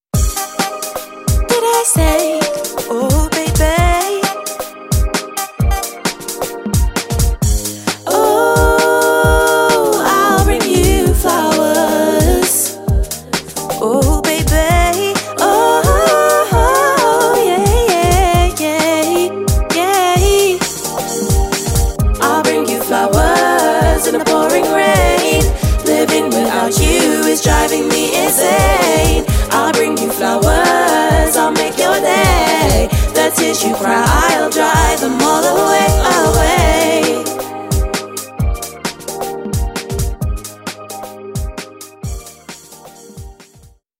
3 x Female Vocals + Professional Backing Tracks